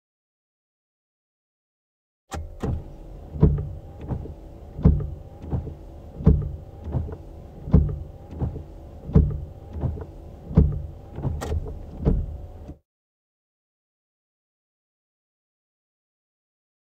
جلوه های صوتی
دانلود صدای شیشه پاک کن 1 از ساعد نیوز با لینک مستقیم و کیفیت بالا
برچسب: دانلود آهنگ های افکت صوتی حمل و نقل دانلود آلبوم صدای شیشه پاک کن ماشین از افکت صوتی حمل و نقل